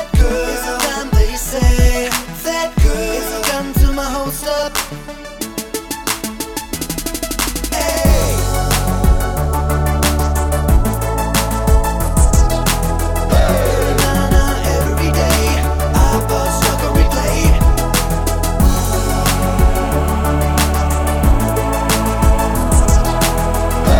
no Backing Vocals R'n'B / Hip Hop 3:08 Buy £1.50